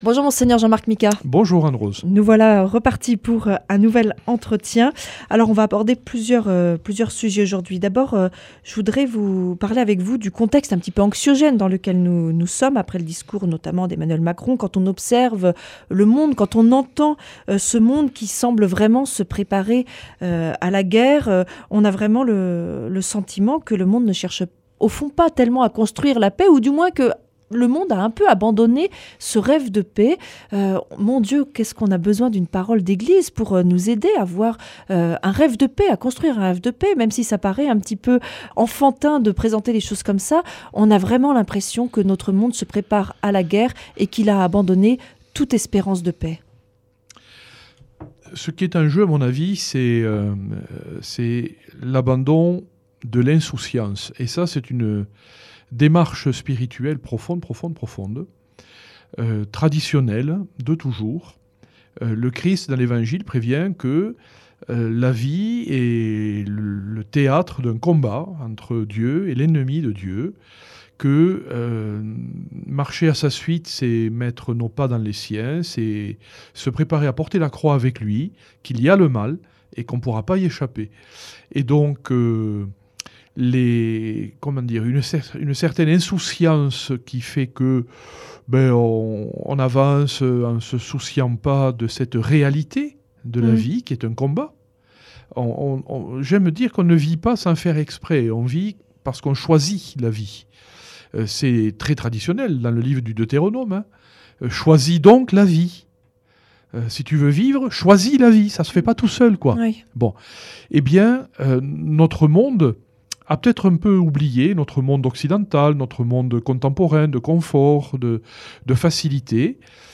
L’entretient de Mgr Jean-Marc Micas cette semaine nous amène à regarder l’actualité au travers du prisme du Christ, seule lumière et espérance pour tous les hommes.